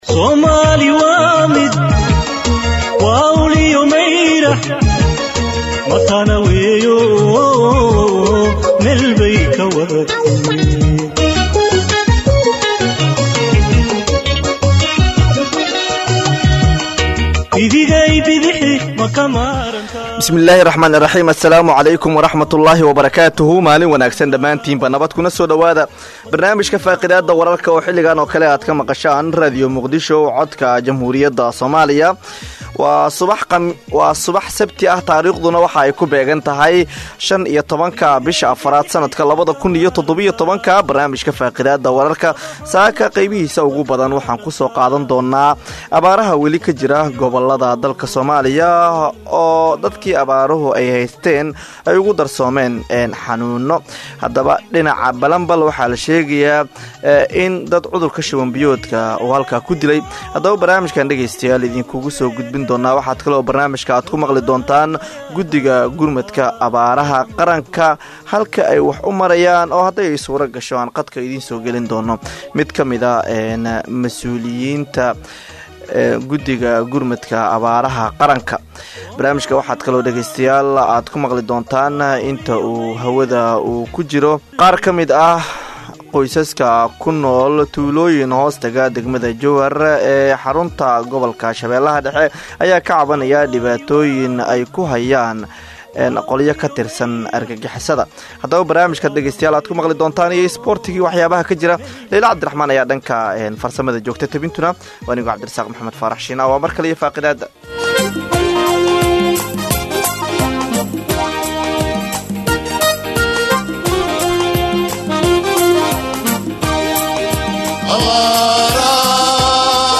Akhristayaasheena sharafta leh waxaan halkaan idinkugu soo gudbineynaa barnaamijka Faaqidaada oo ka baxa Radio Muqdisho subax waliba marka laga reebo subaxda Jimcaha, waxaana uu xambaarsanyahay macluumaad u badan wareysiyo iyo falaqeyn xagga wararka ka baxa Idaacadda, kuwooda ugu xiisaha badan.